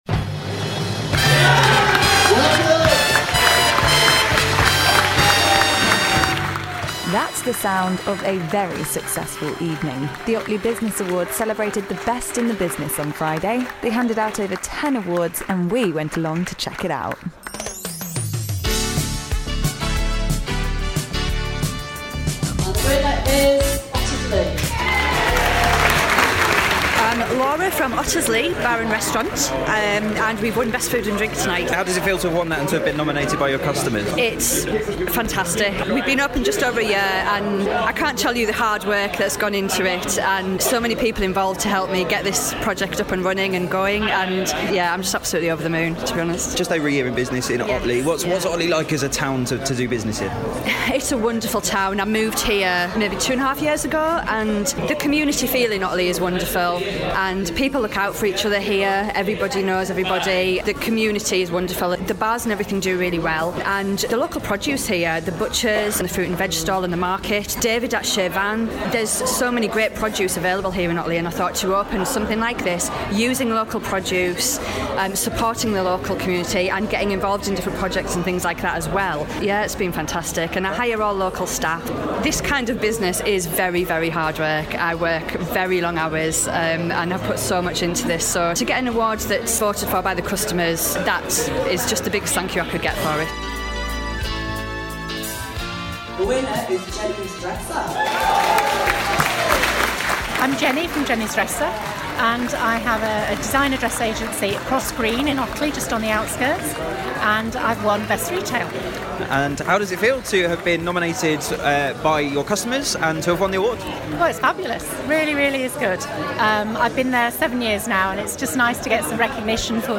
Listen below to find out what happened when we went down to the awards to catch up with the winners.